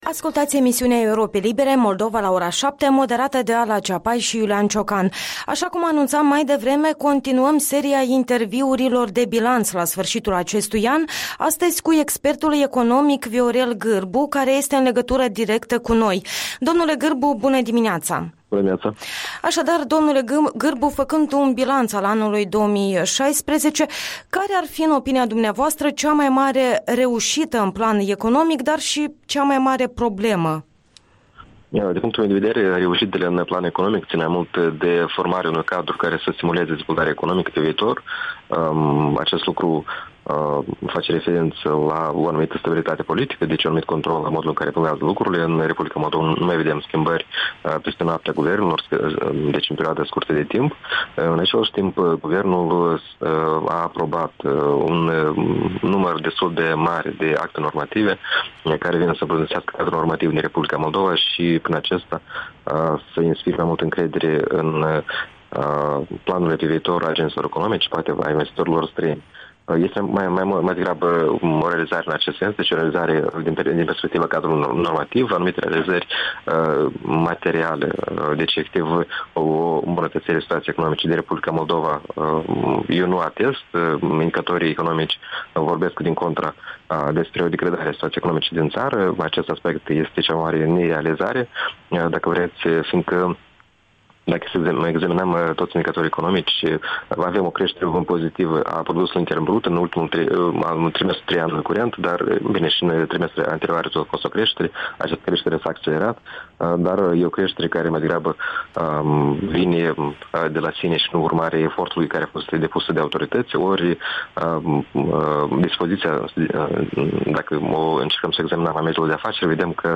Un interviu cu expertul economic de la Chișinău.